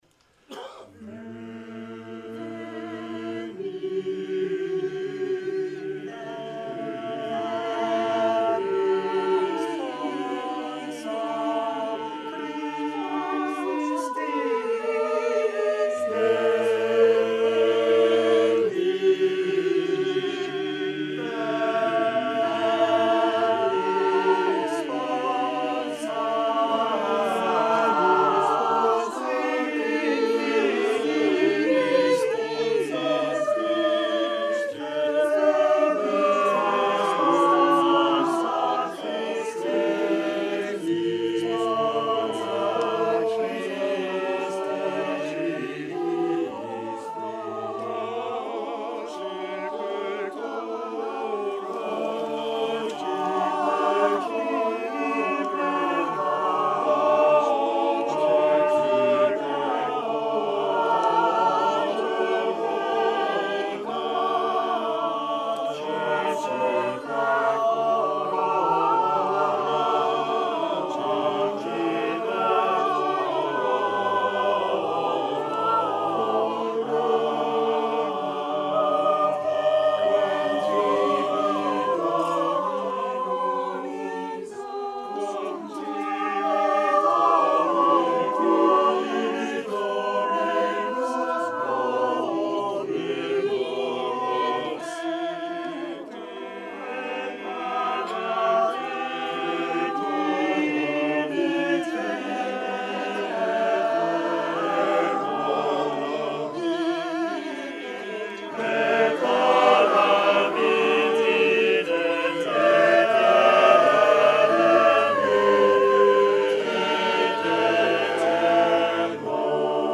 The Renaissance Street Singers' 45th-Anniversary Loft Concert, 2018